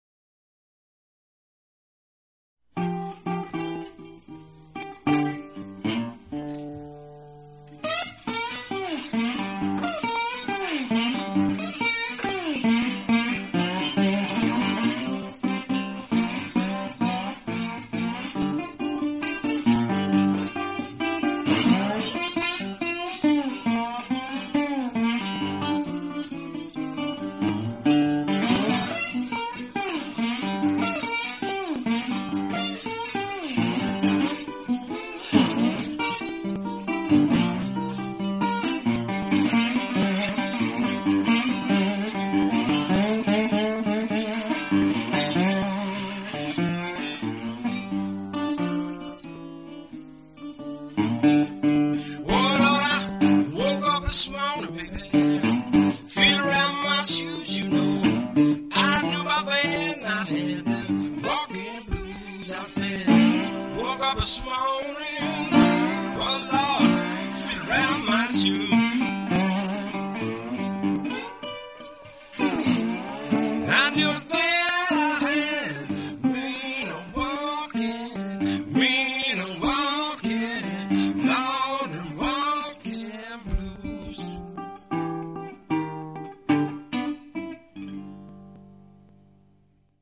(acoustic version)